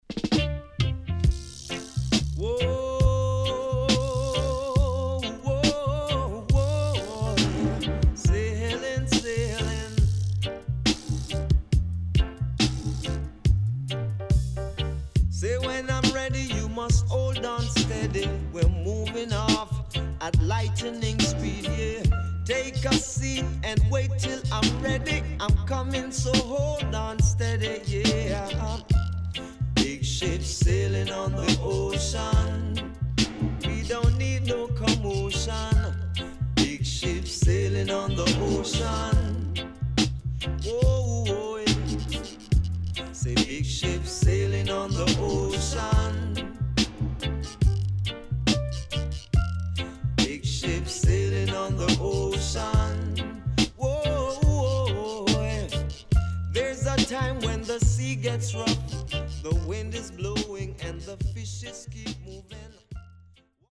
HOME > LP [DANCEHALL]  >  SWEET REGGAE  >  定番70’s
SIDE A:少しチリノイズ入りますが良好です。